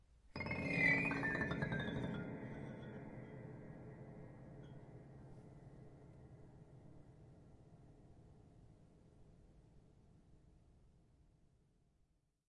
恐怖的钢琴弦乐滑音下来高弦乐
描述：用旧立式钢琴的琴弦产生令人毛骨悚然的声音。用手指在钢琴弦上向下滑动了一个短暂的滑音。阻尼踏板压住了。记录为ZOOM H1。
标签： 琴弦 悬念 闹鬼 滑音 令人毛骨悚然 效果 惊悚 怪异 戏剧 恐怖
声道立体声